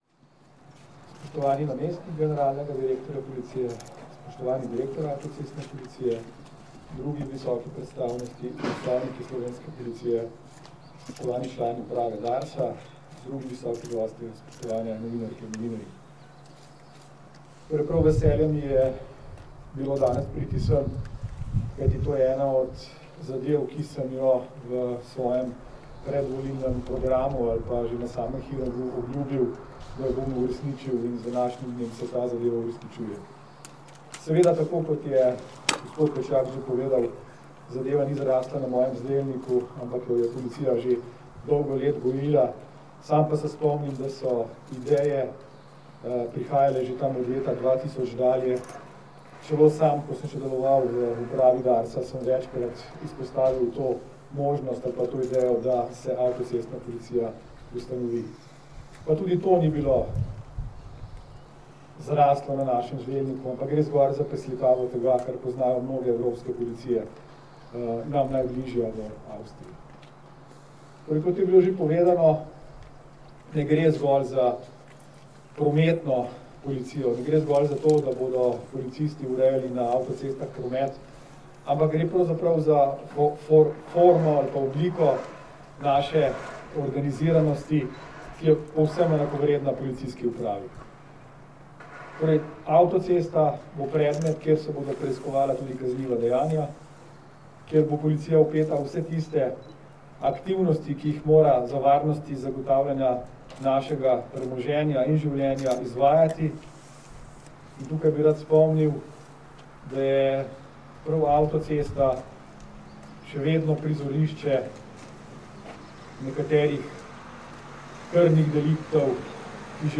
Dogodka ob otvoritvi UAP so se v Postojni, kjer je sedež novoustanovljene uprave, danes, 31. marca 2021, iz MNZ in Policije udeležili minister za notranje zadeve Aleš Hojs, namestnik generalnega direktorja policije Tomaž Pečjak in direktor uprave Andrej Jurič.
Zvočni posnetek govora ministra za notranje zadeve Aleša Hojsa
hojs.mp3